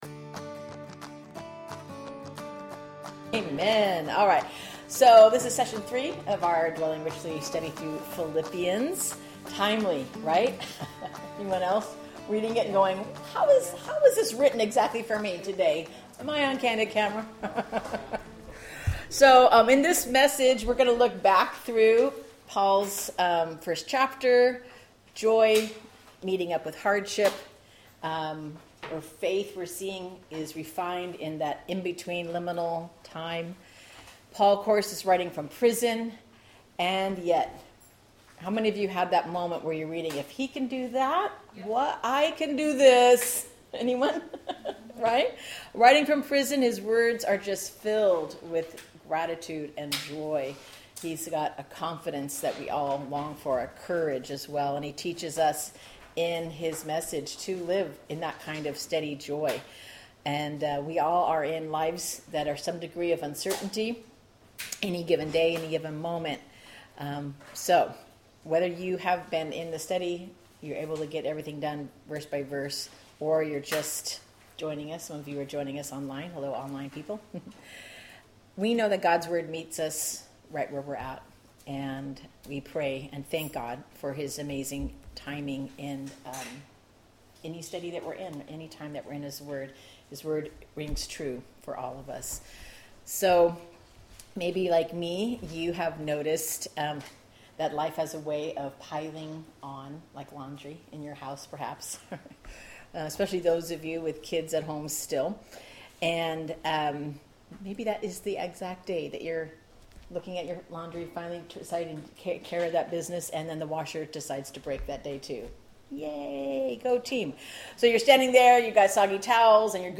A community Bible study: Dwelling Richly through Philippians